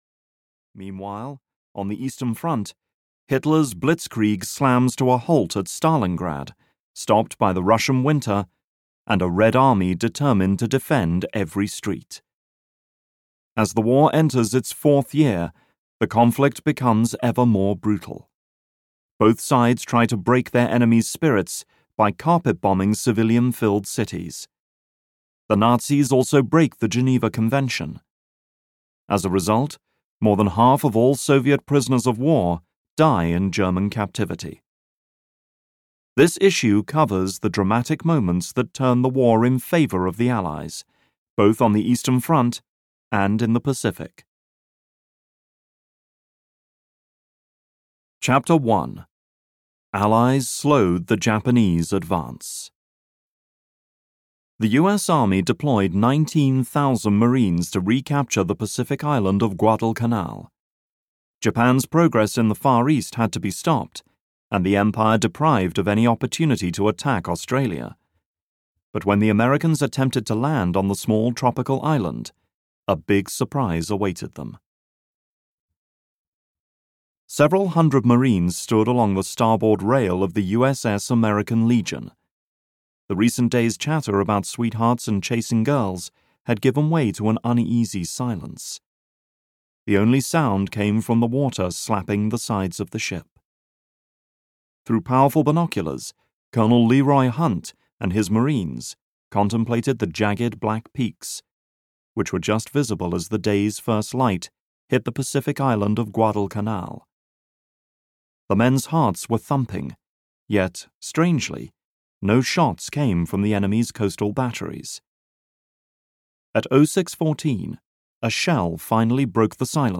The Allies Fight Back (EN) audiokniha
Ukázka z knihy